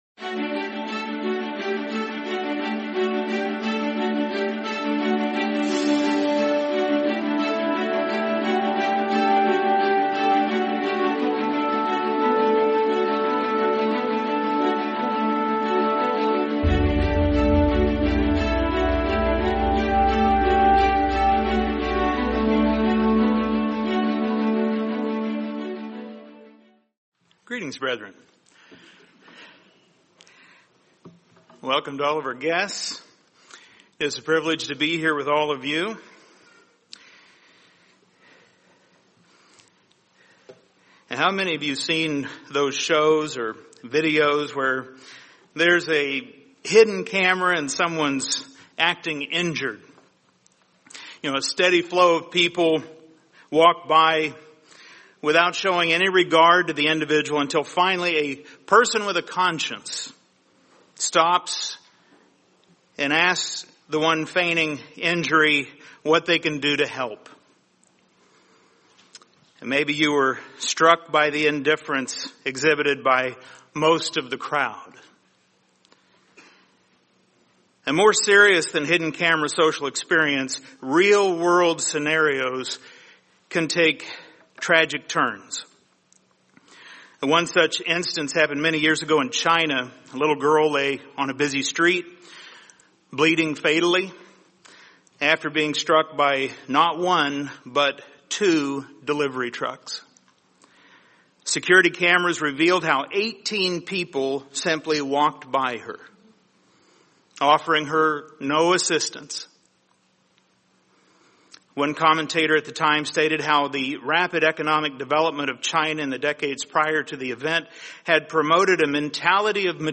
| Sermon | LCG Members